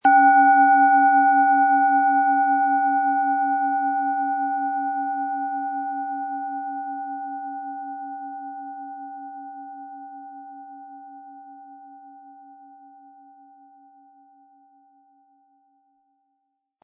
Planetenton
Im Sound-Player - Jetzt reinhören können Sie den Original-Ton genau dieser Schale anhören.
Lieferung inklusive passendem Klöppel, der gut zur Planetenschale passt und diese sehr schön und wohlklingend ertönen lässt.
SchalenformBihar
MaterialBronze